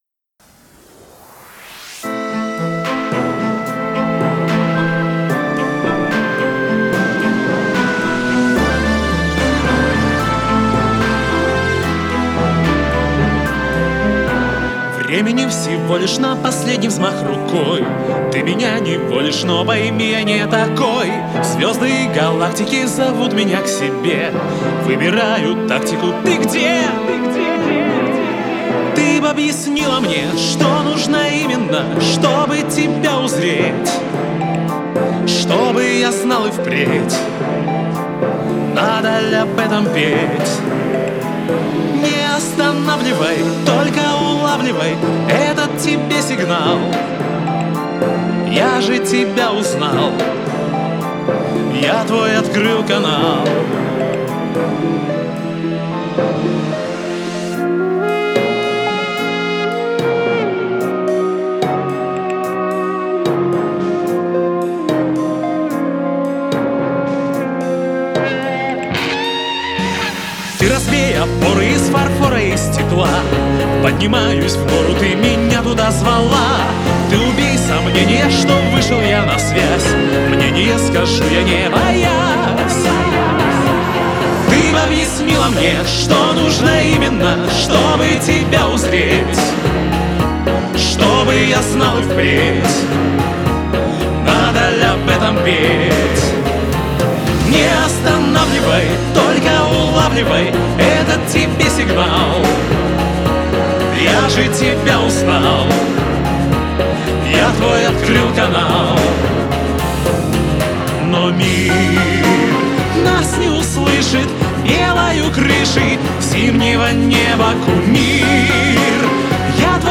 mp3,7783k] Поп
Тональность изменена под сегодняшний голос.